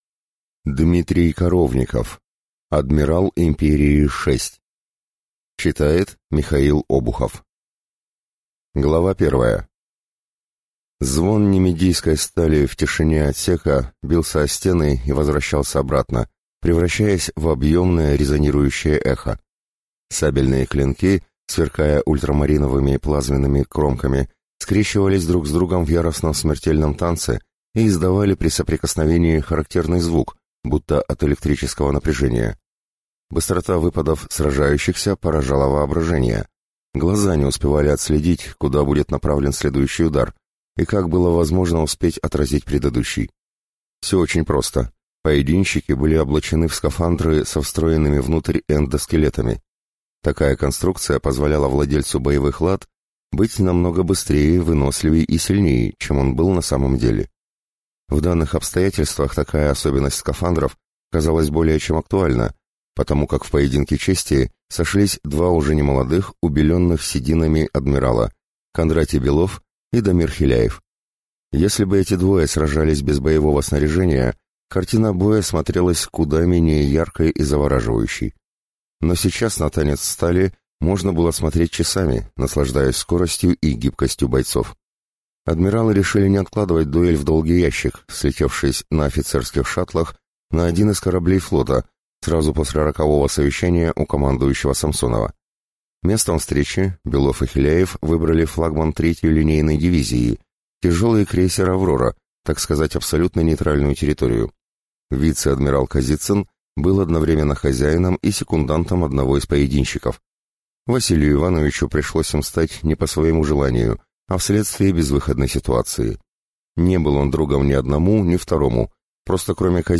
Аудиокнига Адмирал Империи – 6 | Библиотека аудиокниг